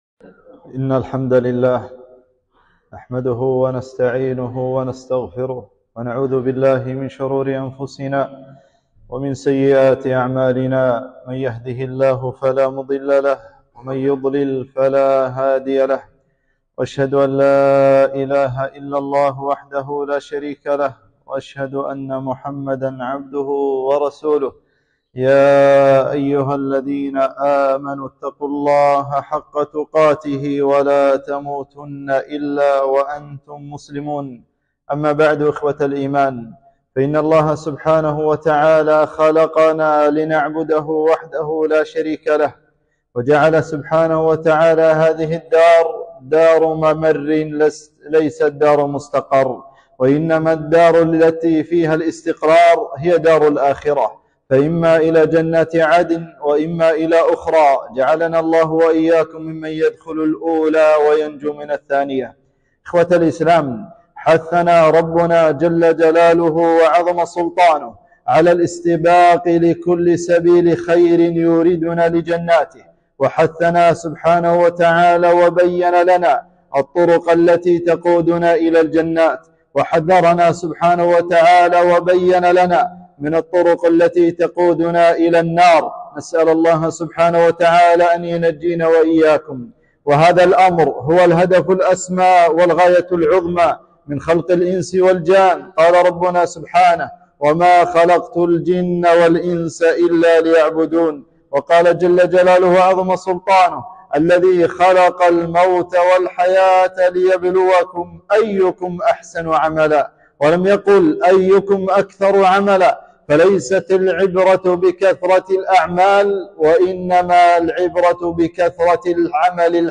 خطبة - الأكثار من ذكر الله